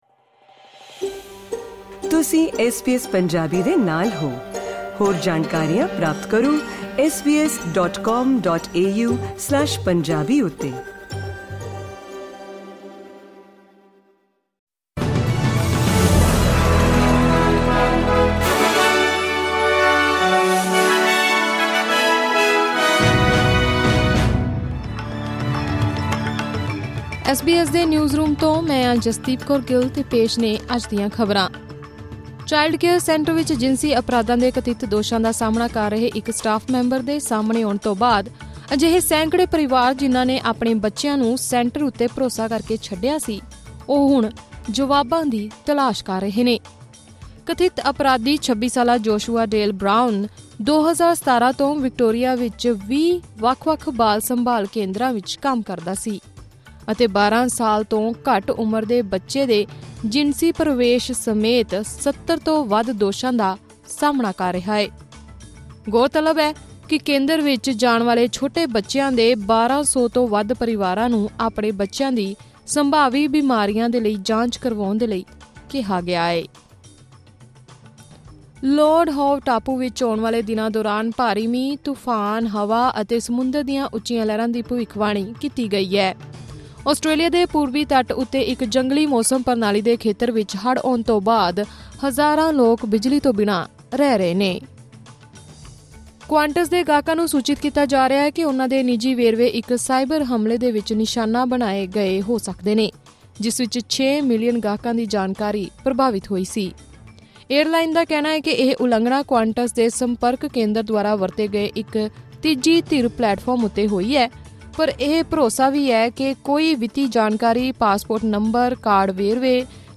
ਖ਼ਬਰਨਾਮਾ: ਵਿਕਟੋਰੀਆ ਦੇ ਬਾਲ ਸੰਭਾਲ ਕੇਂਦਰ ਵਿੱਚ ਬੱਚਿਆਂ ਨਾਲ ਸ਼ੋਸ਼ਣ ਮਾਮਲੇ 'ਚ ਮਾਪੇ ਕਰ ਰਹੇ ਜਵਾਬਾਂ ਦੀ ਭਾਲ